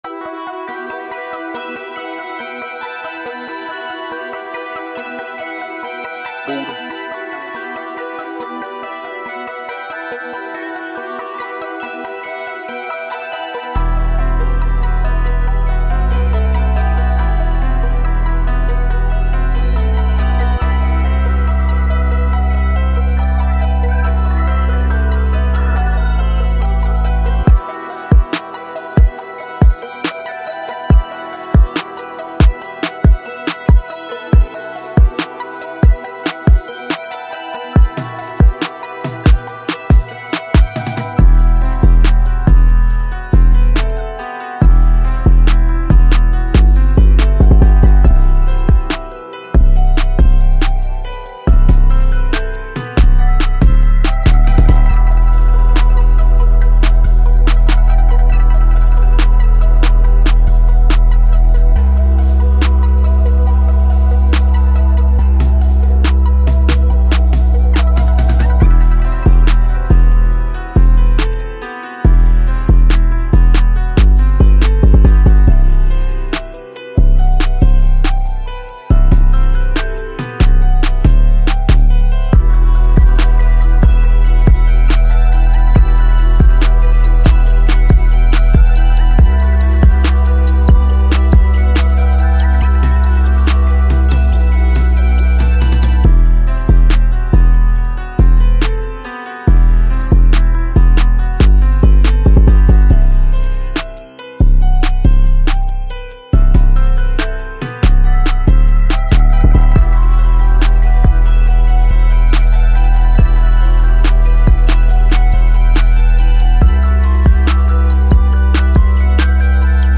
a dynamic Drill Rap Instrumental